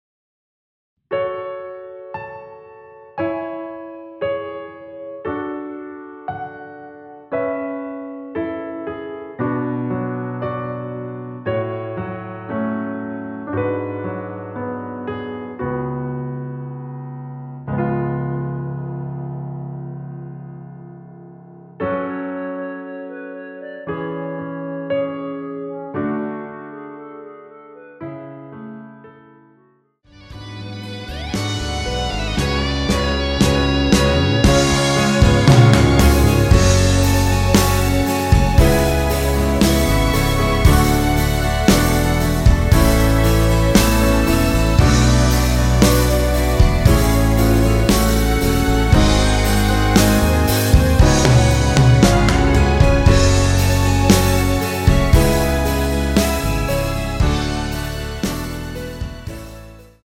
원키에서(-1)내린 멜로디 포함된 MR입니다.
F#m
멜로디 MR이라고 합니다.
앞부분30초, 뒷부분30초씩 편집해서 올려 드리고 있습니다.
중간에 음이 끈어지고 다시 나오는 이유는